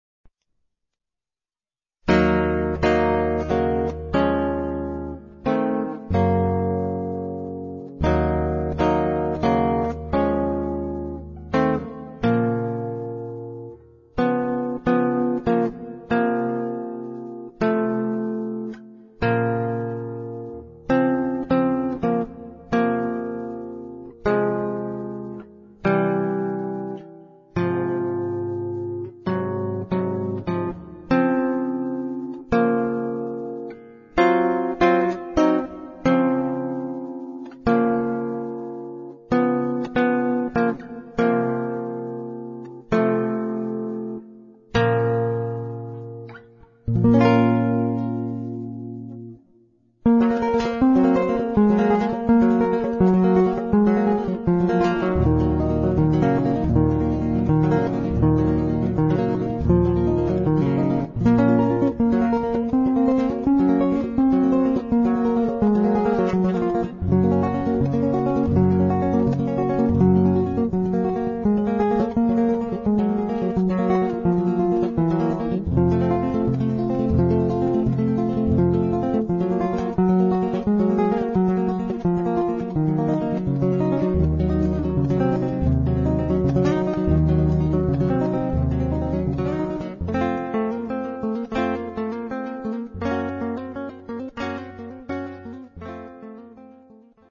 in Brasilian styles
Baião